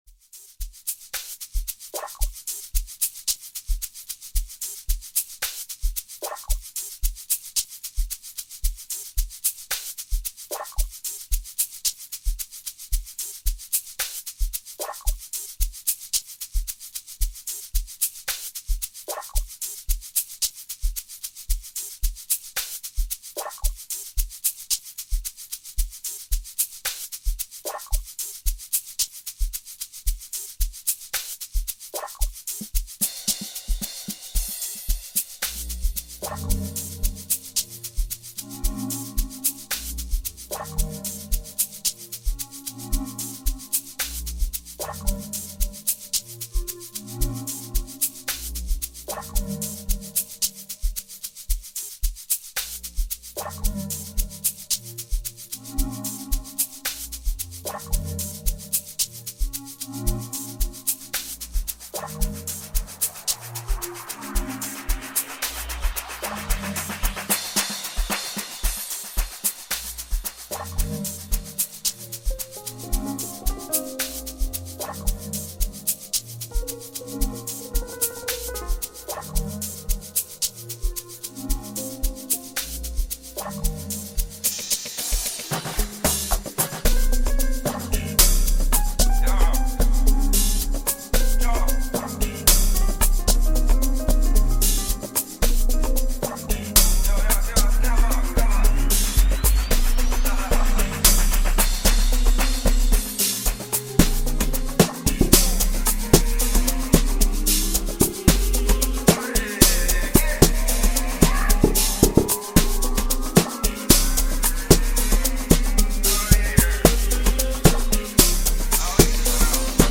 This has been the way of life for most Amapiano producers.